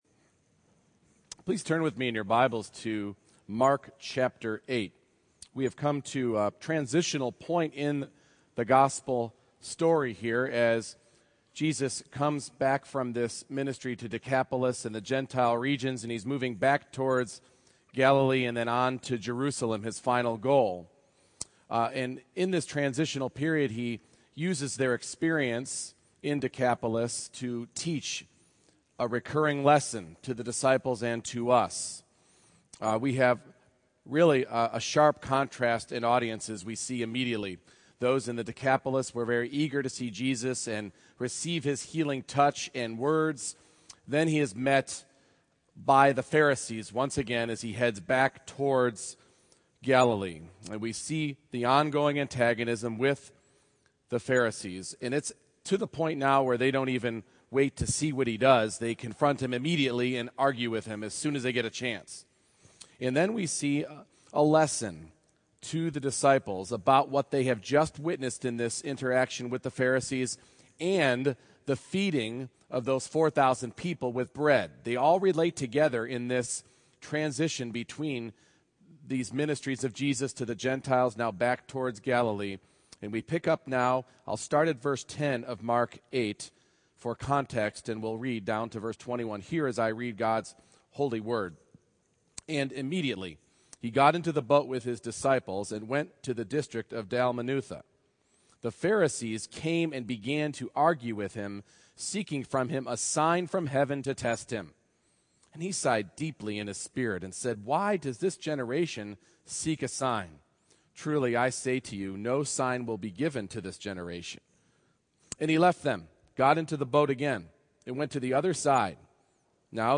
Mark 8:10-21 Service Type: Morning Worship We are warned about allowing our thinking to be molded and assimilated to that of the world around us.